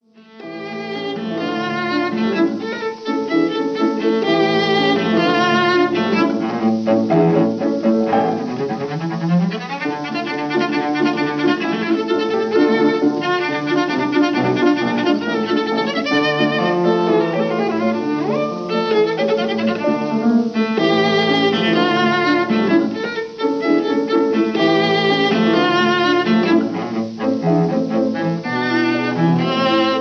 cello
piano